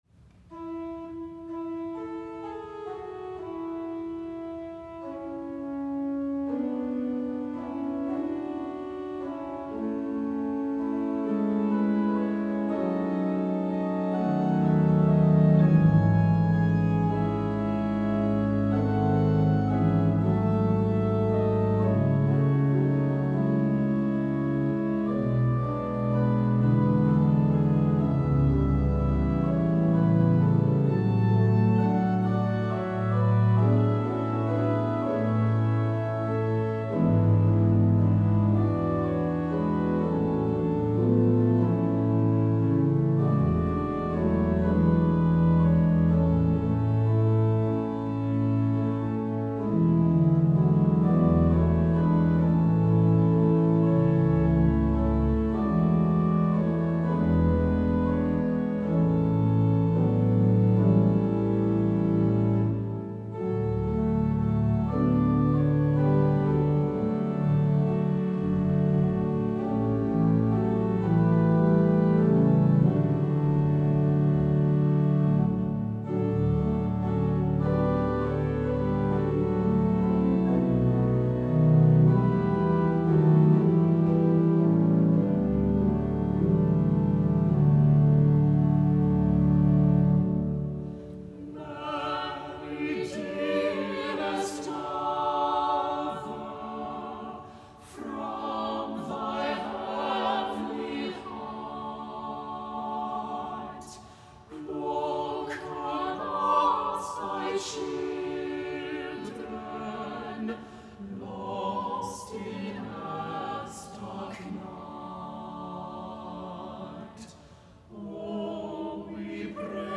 Choir: